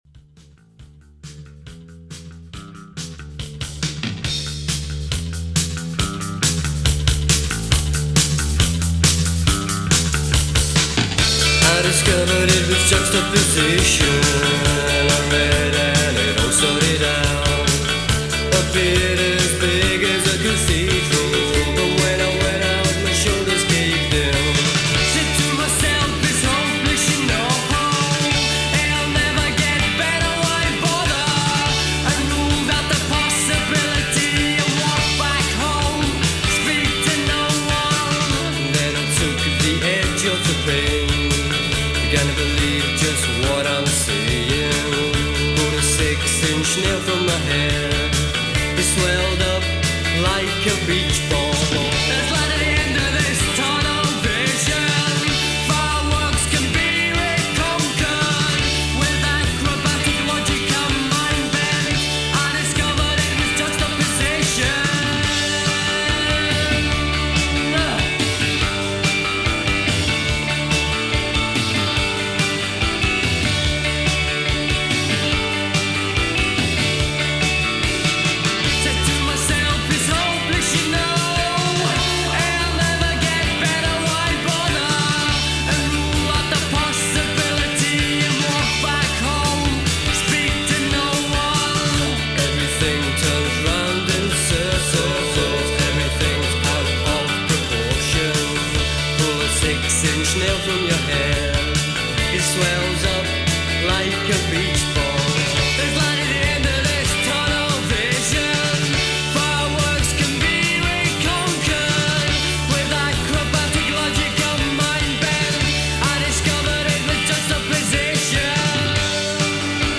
Indie band